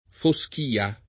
foSk&a] s. f. — es. con acc. scr.: nelle foschìe crepuscolari [nelle foSk&e krepuSkol#ri] (D’Annunzio); la foschìa s’addensava minacciosa [